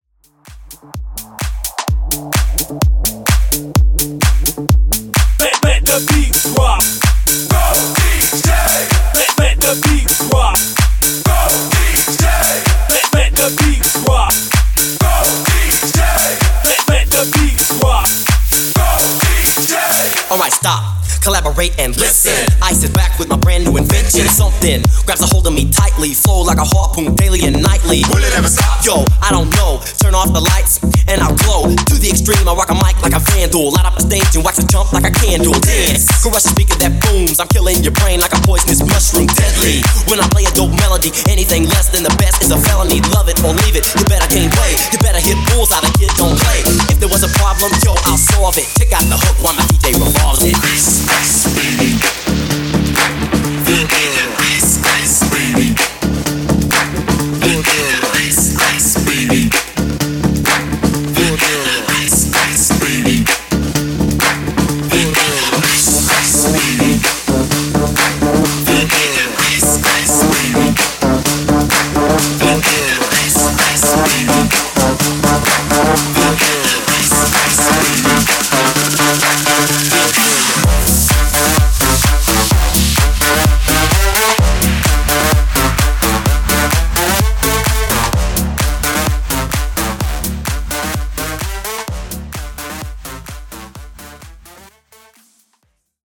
Club Hype Edit)Date Added